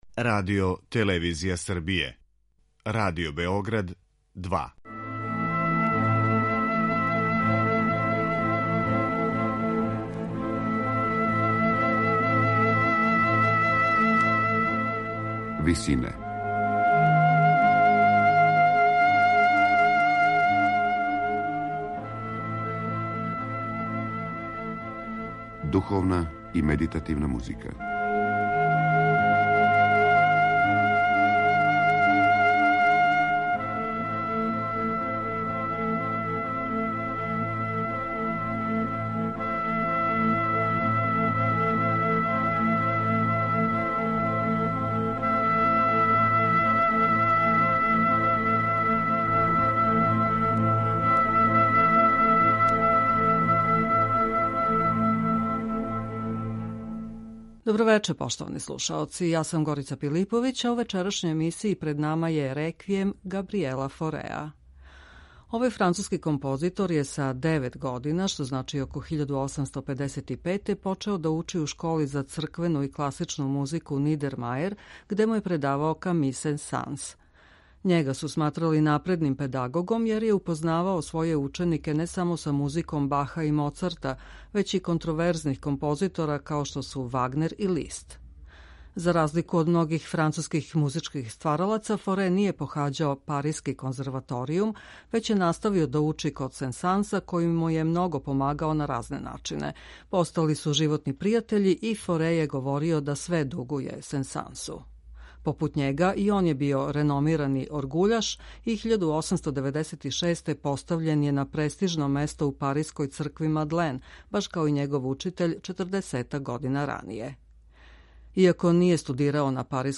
Зато је и његов Реквијем управо по том интимистичком изразу сасвим различит од величанствених остварења других аутора - Вердија, Брамса или Берлиоза.